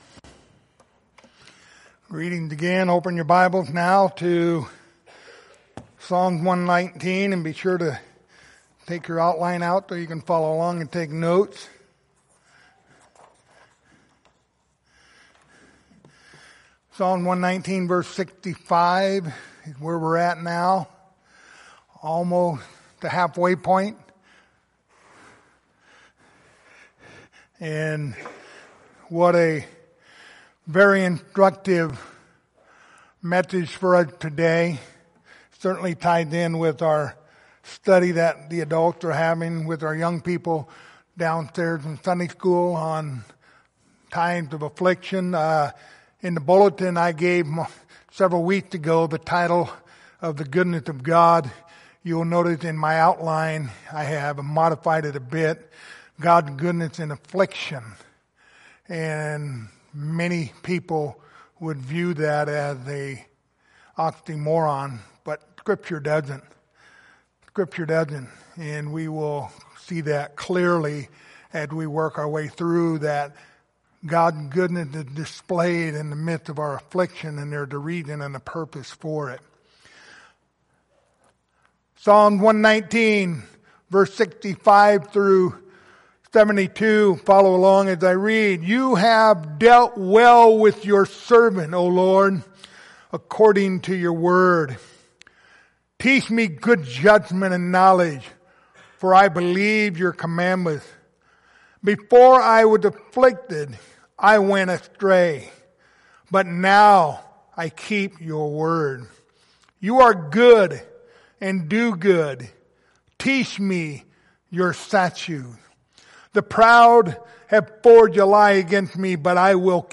Psalm 119 Passage: Psalms 119:65-72 Service Type: Sunday Morning Topics